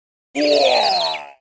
Barbarian King Die Clash Of Clans Sound Effect Free Download